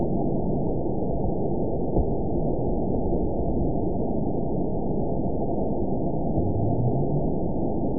event 920644 date 04/02/24 time 02:05:50 GMT (1 year, 8 months ago) score 9.22 location TSS-AB02 detected by nrw target species NRW annotations +NRW Spectrogram: Frequency (kHz) vs. Time (s) audio not available .wav